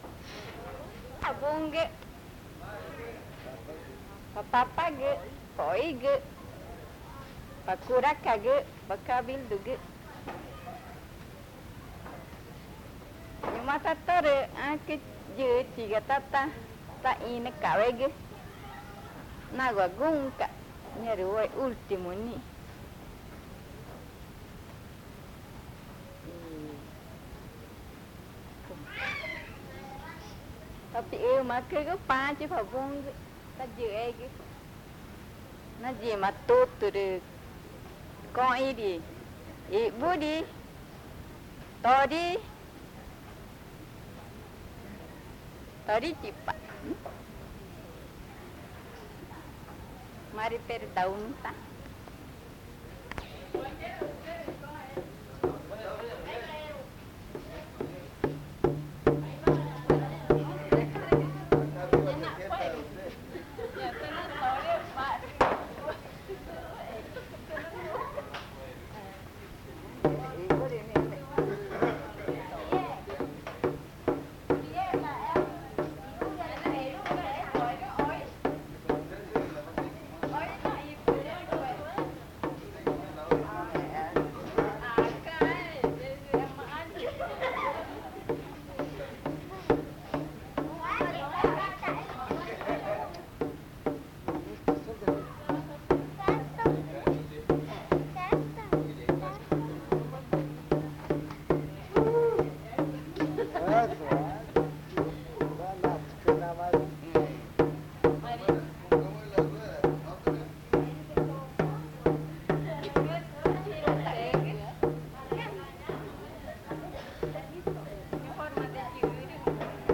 Canto de la Taricaya
El Vergel, Amazonas (Colombia)
Una abuela anónima canta sobre la tortuga Taricaya, utilizando el tambor y el cascabel.
An anonymous elder woman sings about the taricaya turtle, using a drum and a rattle made out of cascabel seed.